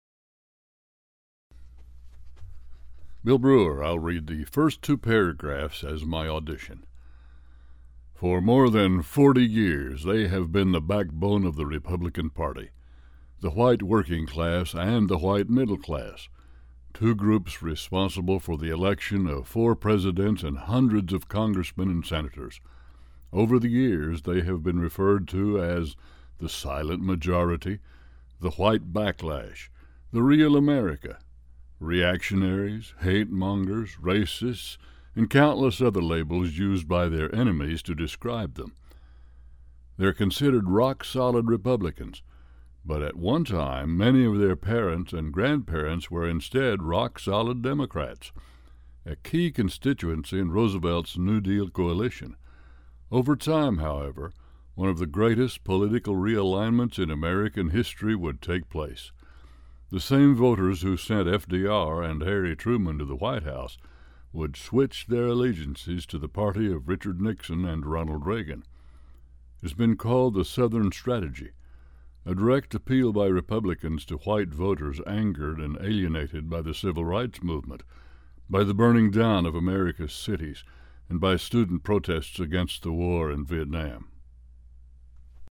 Sprechprobe: Werbung (Muttersprache):
A Bass/Baritone with a hint of the Southwest